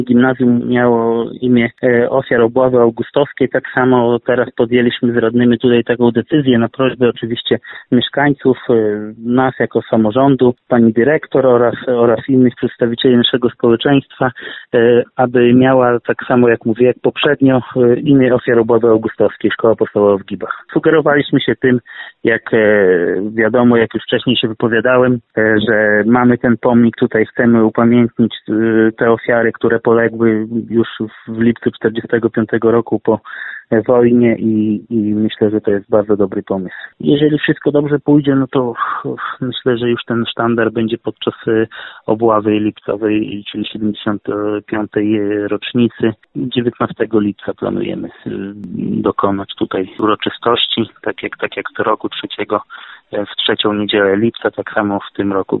Jak tłumaczy Radiu 5 Robert Bagiński, wójt gminy, imię Ofiar Obławy Augustowskiej miało zlikwidowane gimnazjum.